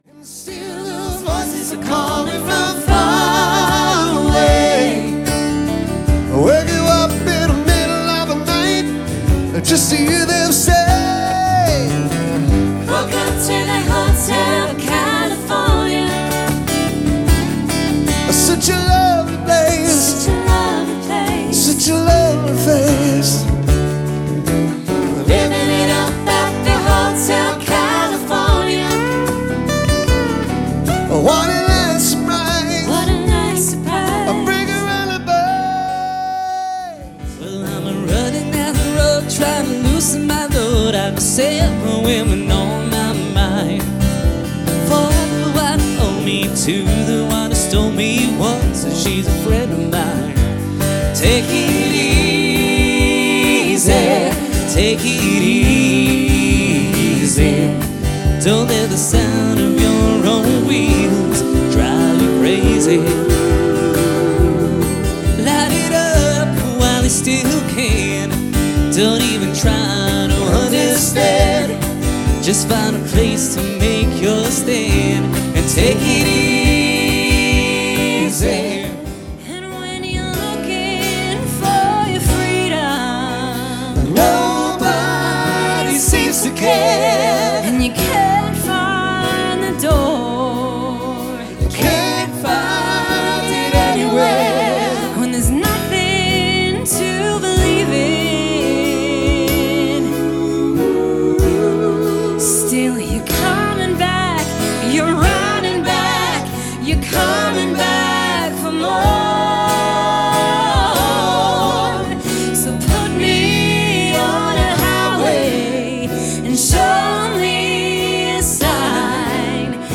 laid back acoustic tribute